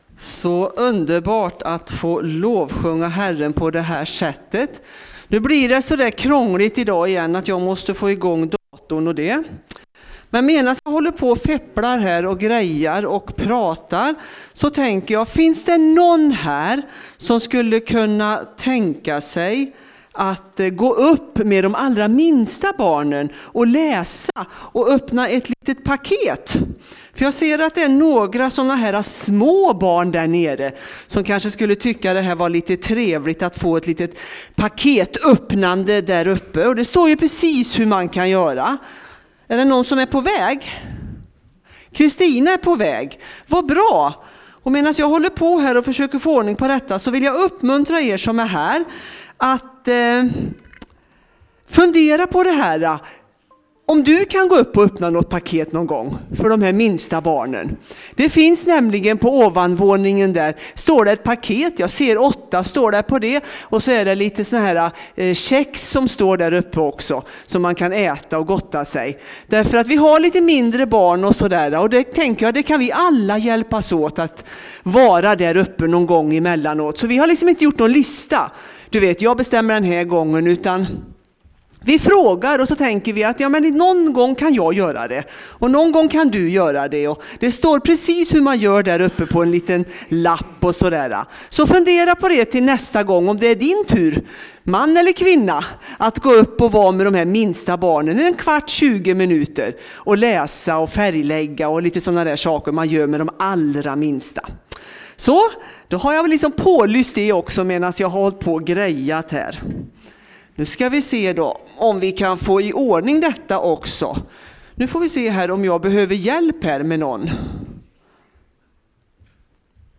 Predikan